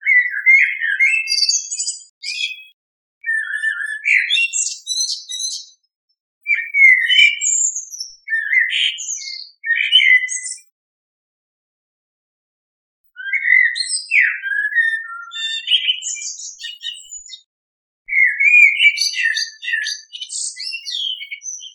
Hallgass bele csodálatos énekébe és képzeld el, hogy reggel az ébresztőóra zúgása helyett dalos kedvű rigó fütyül a fákon.
A rigó hangja
Turdus_merula_male_song_at_dawn20s.ogg.mp3